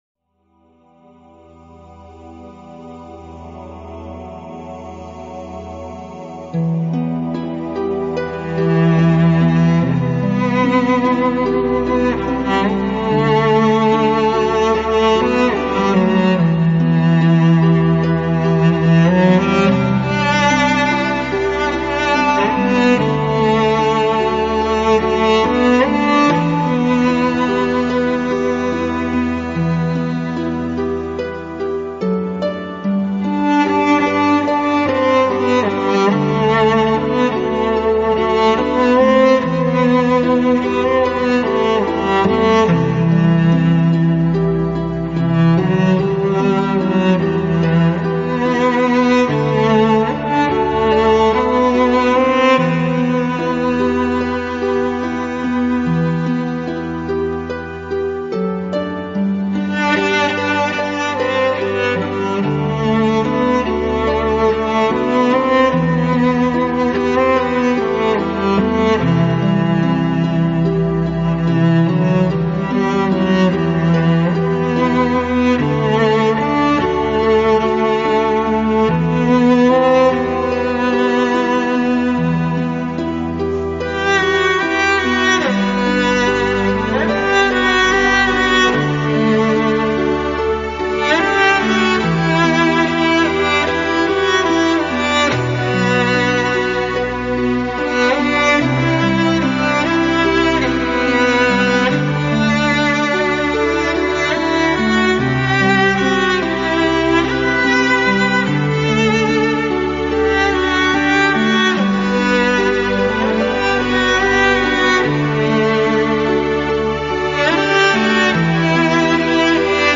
其浑厚的低音悲伤之意浓厚